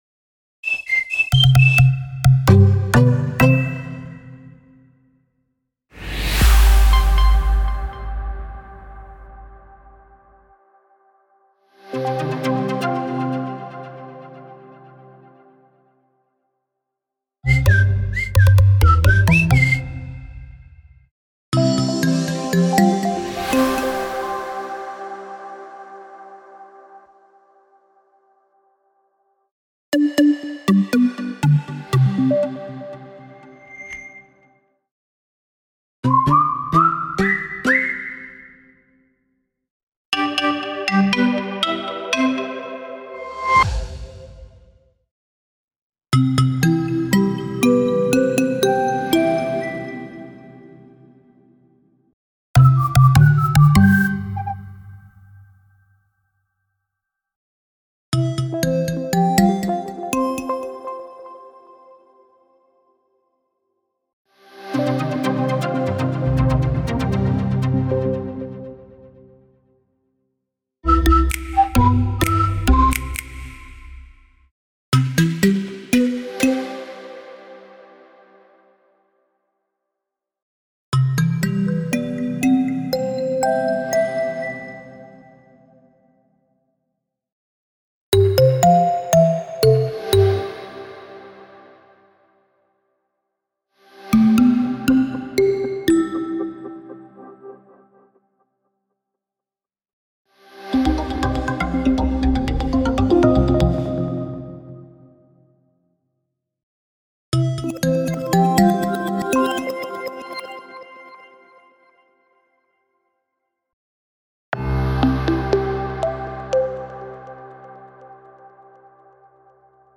20 X free audio marks for your logo